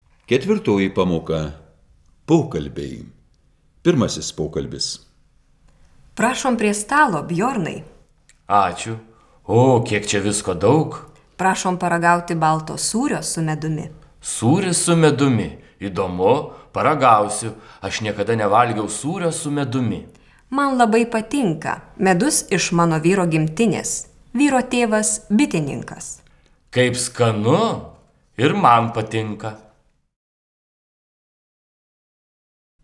04_Dialog_1.wav